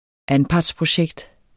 Udtale [ ˈanpɑds- ]